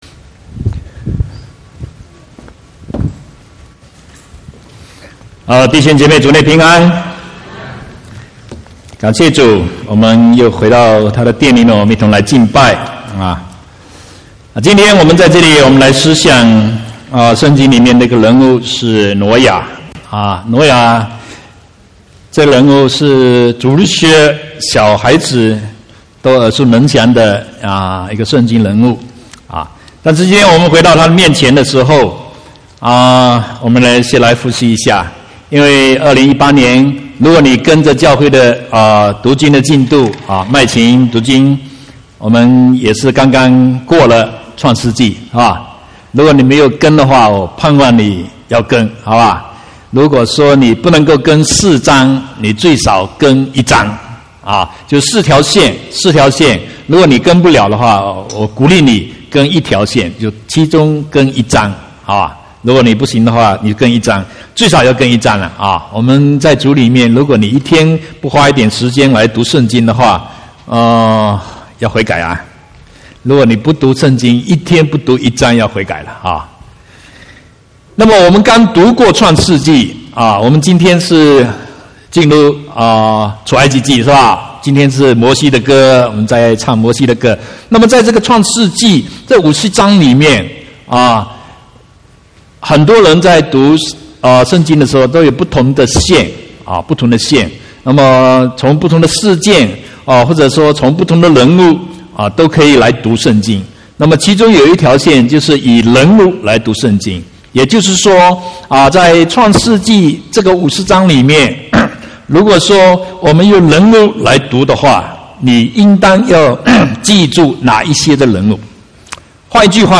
4/3/2018 國語堂講道